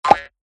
SFX_WordPopup.mp3